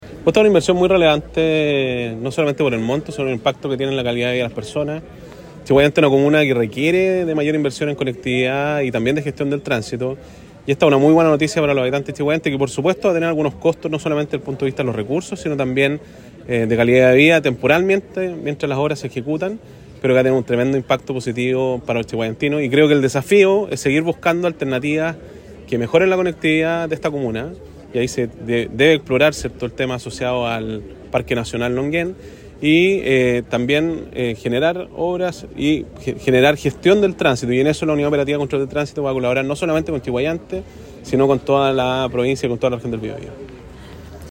El inicio de obras contó con la presencia de autoridades regionales, quienes concordaron en destacar la importancia de la iniciativa que busca mejorar la conectividad urbana de la comuna, así como también potenciar la seguridad vial en el entorno de la nueva infraestructura.
Para el gobernador regional, Sergio Giacaman, el inicio de las obras representa una gran oportunidad para toda la comunidad chiguayantina.